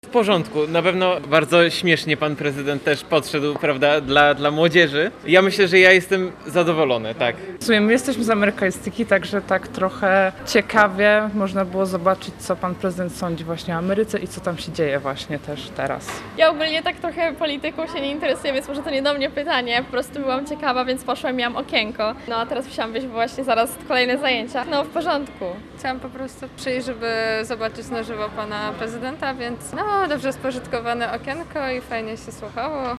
Sala Teatralna im. prof. Jerzego Limona w budynku Neofilologii Uniwersytetu Gdańskiego była wypełniona po brzegi.